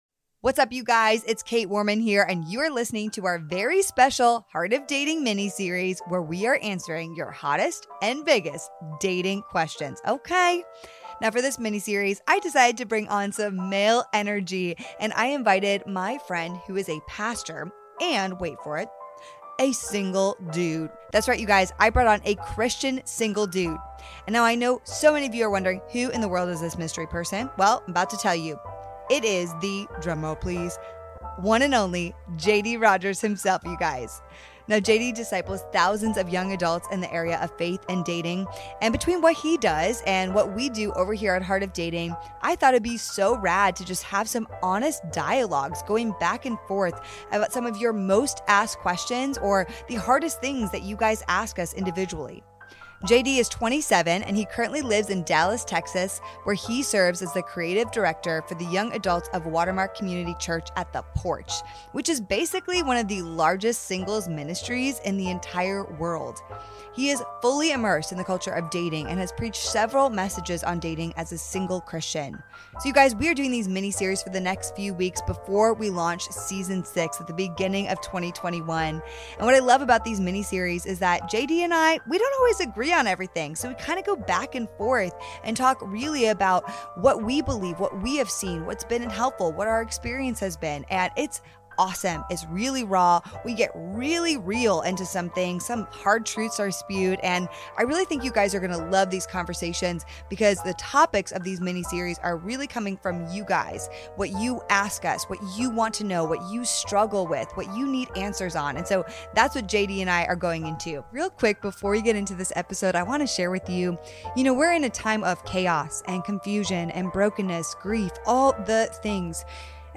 fun conversation